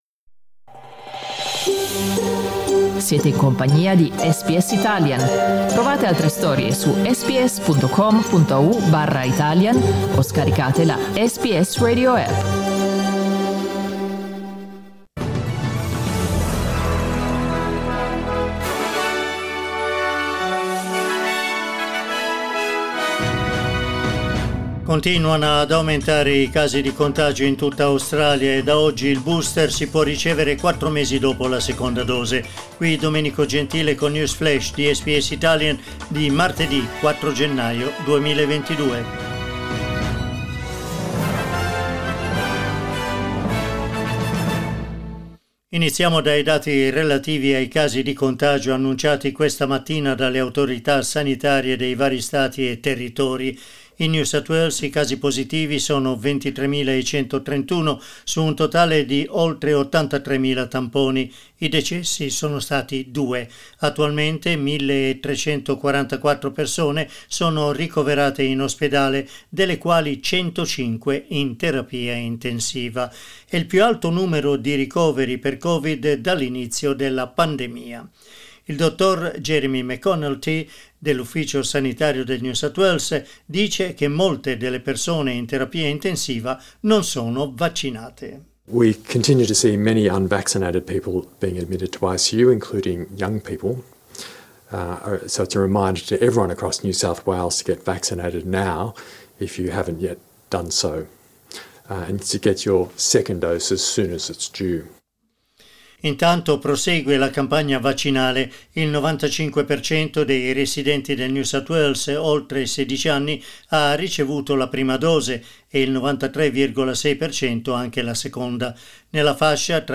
News flash martedì 4 gennaio 2022